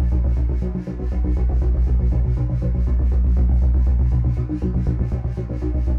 Index of /musicradar/dystopian-drone-samples/Tempo Loops/120bpm
DD_TempoDroneE_120-B.wav